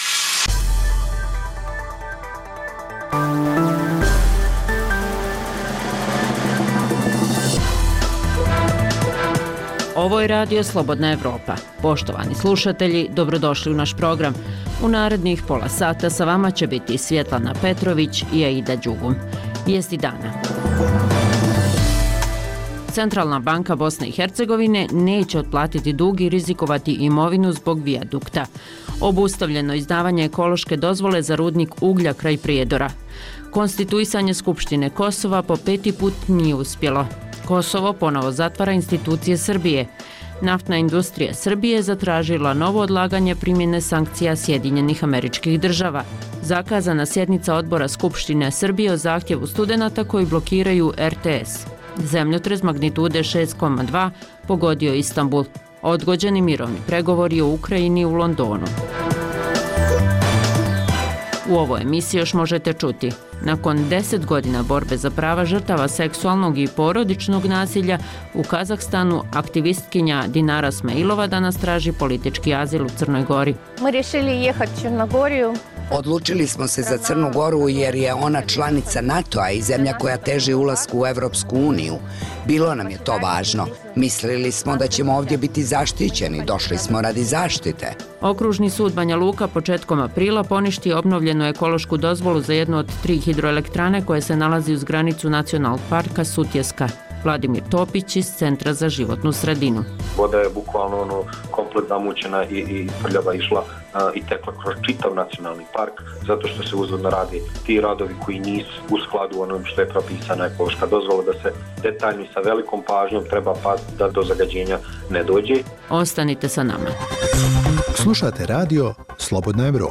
Reportaže iz svakodnevnog života ljudi su svakodnevno takođe sastavni dio “Dokumenata dana”.